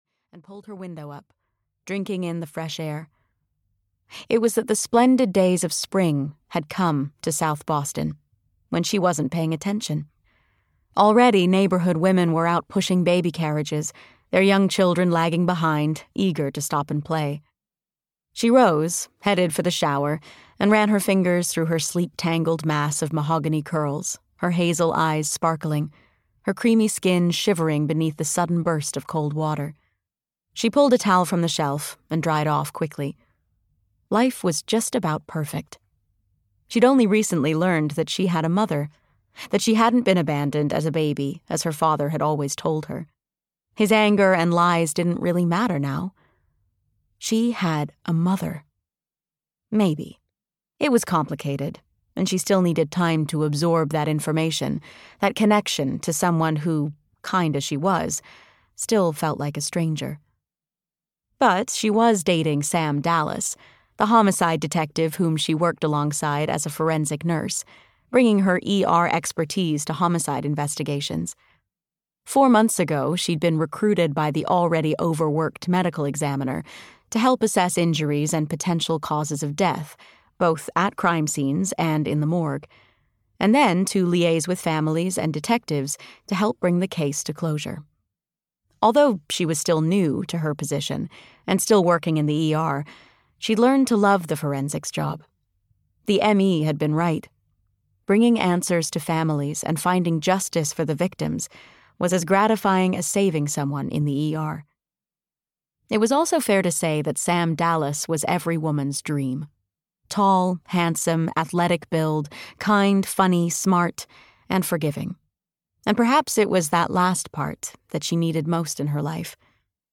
Her Mother's Cry (EN) audiokniha
Ukázka z knihy